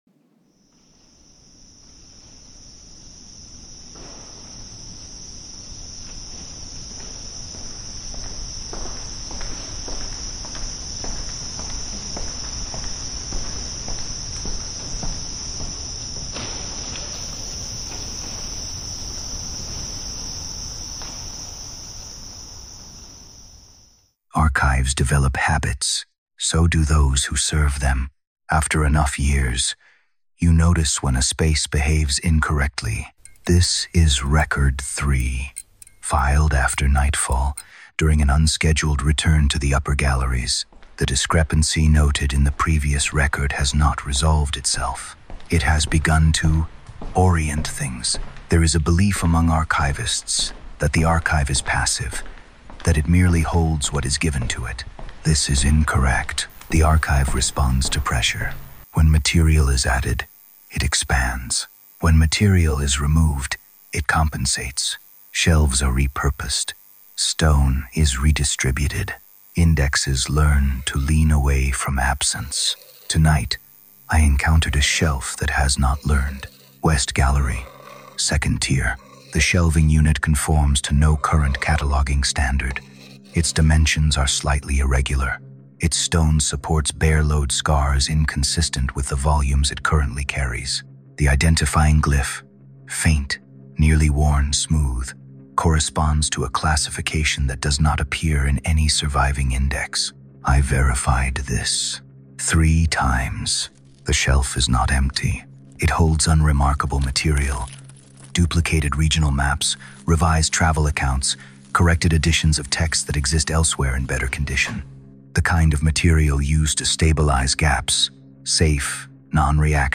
Record 03: The Shelf That Should Not Exist – Narration